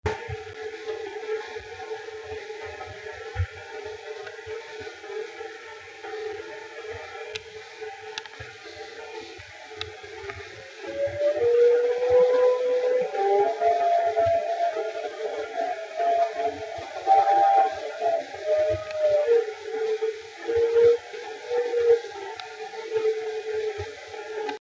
Wind-Blowing.mp3